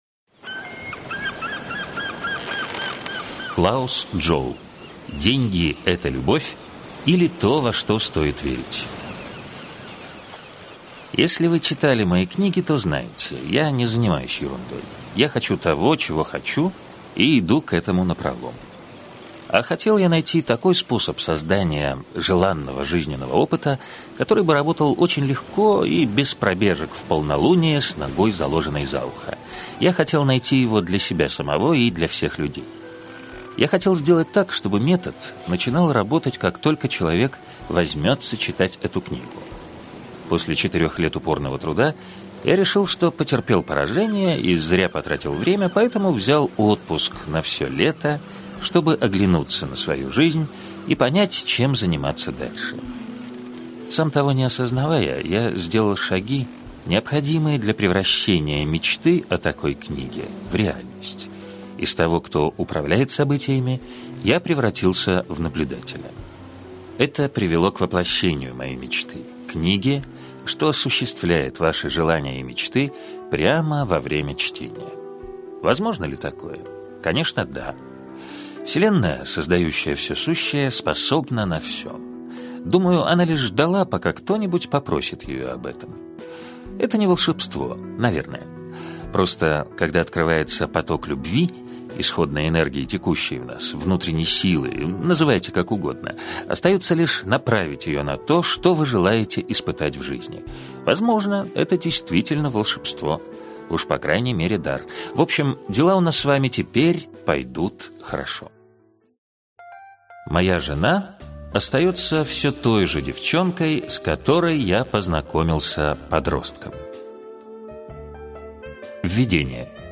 Аудиокнига Деньги – это любовь, или То, во что стоит верить. Том 1 | Библиотека аудиокниг